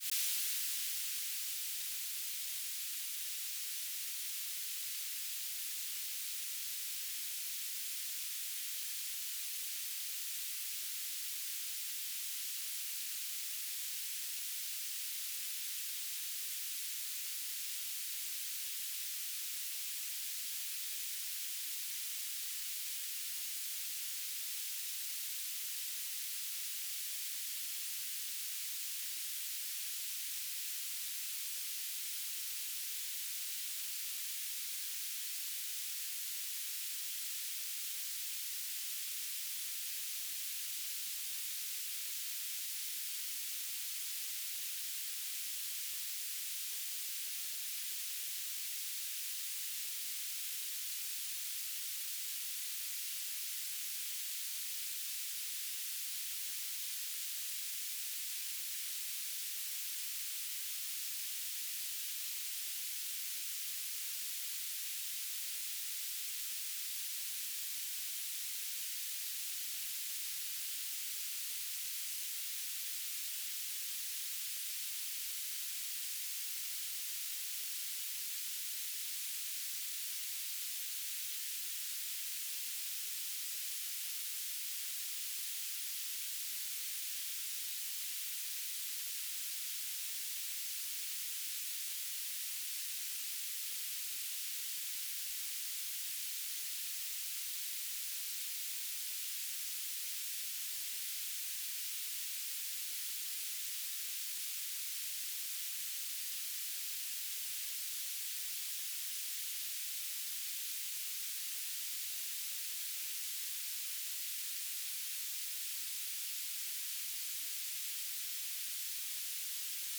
"transmitter_description": "S-band telemetry",
"transmitter_mode": "BPSK",